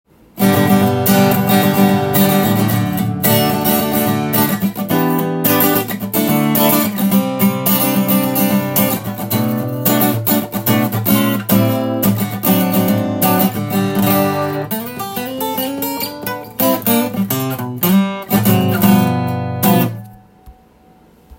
ブラックのボディーが少し小さめのアコースティックギターで
試しに弾いてみました。
ボディーが小さいですが意外と鳴り、音が大きくてビックリしました。